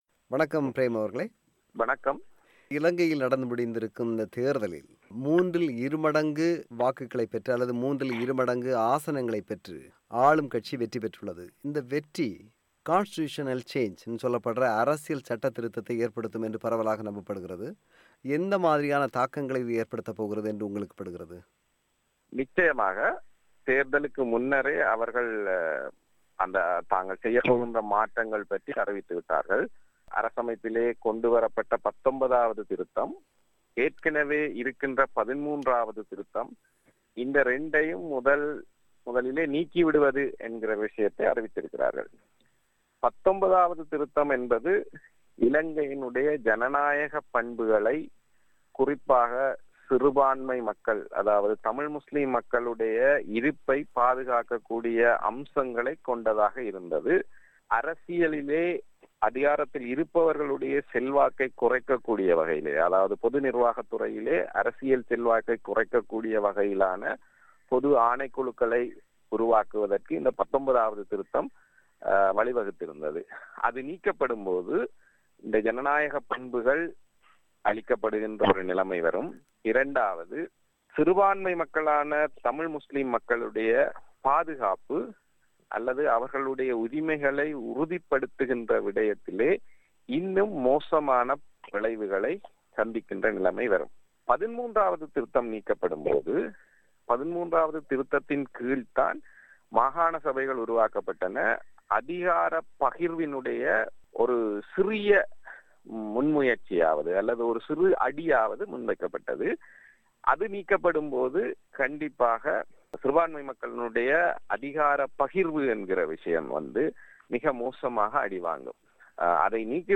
அவரோடு உரையாடுகிறார்